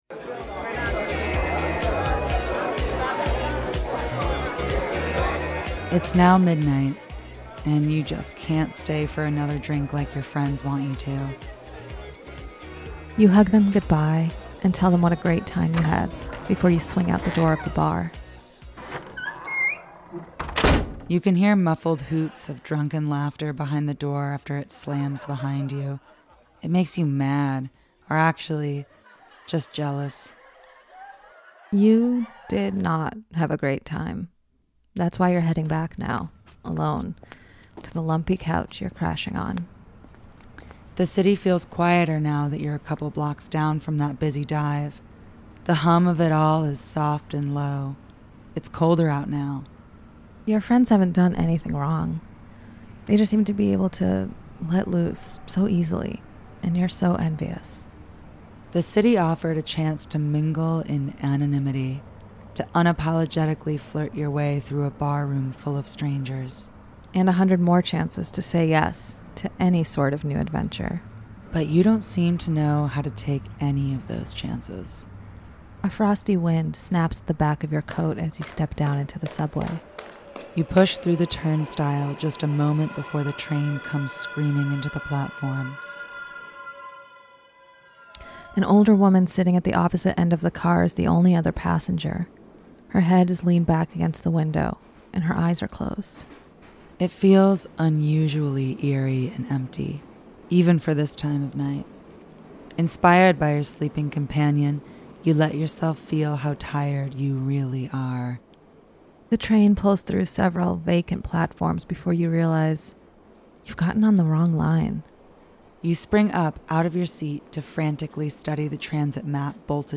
The Love Motel is a monthly radio romance talk show with love songs, relationship advice, and personals for all the lovers in the upper Hudson Valley.